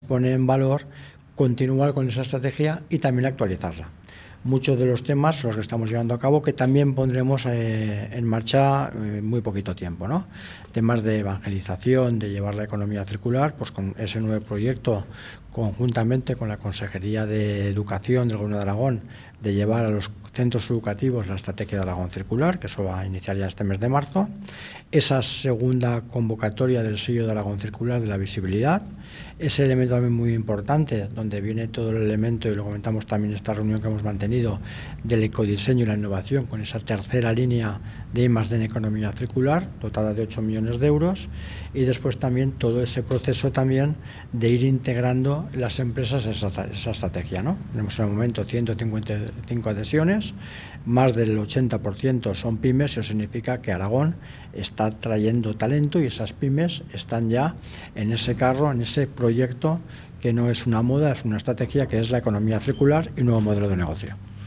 Miguel Luis Lapeña sobre el proceso participativo desarrollado junto a empresas y las acciones de la estrategia